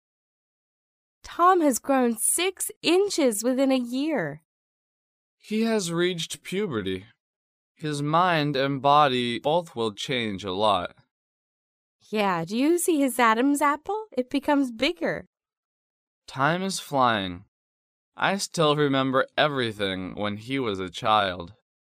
英语情景对话：